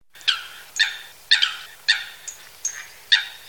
foulque.mp3